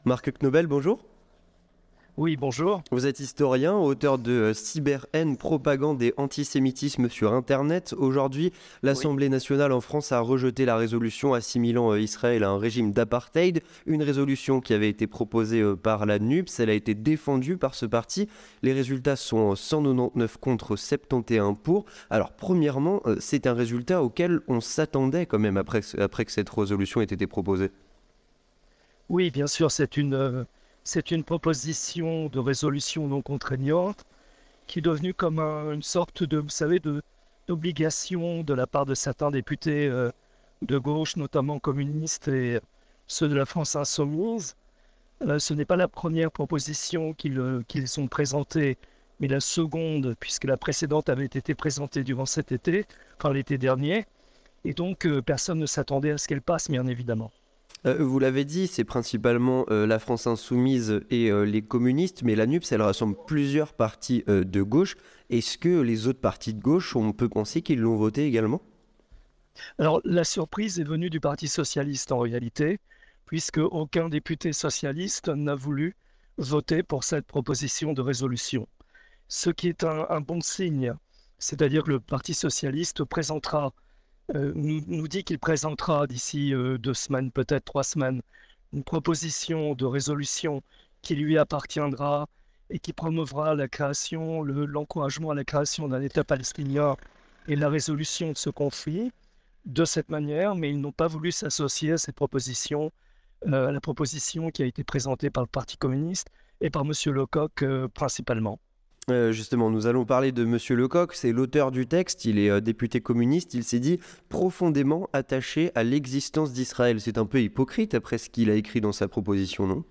Entretien du 18h - Le rejet d'une résolution qualifiant Israël de régime d'apartheid